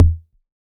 RDM_TapeB_SR88-Kick.wav